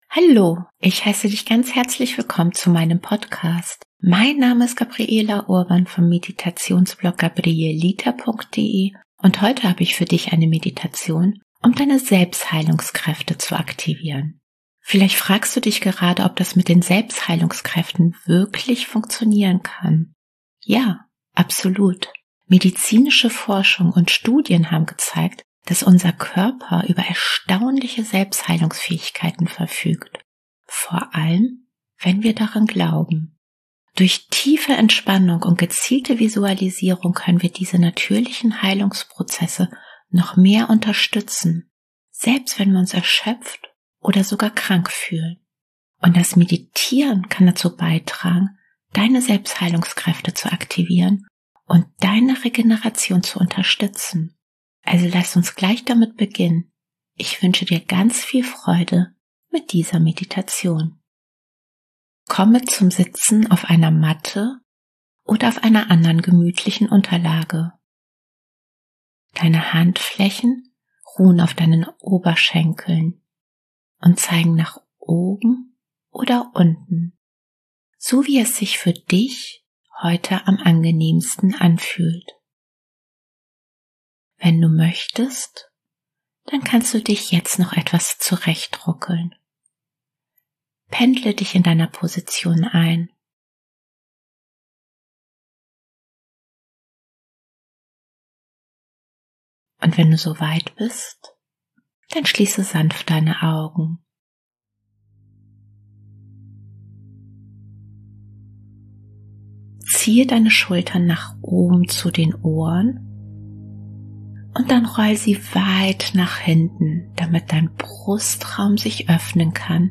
Dann habe ich heute für dich eine wohltuende Meditation, um deine Selbstheilungskräfte zu aktivieren.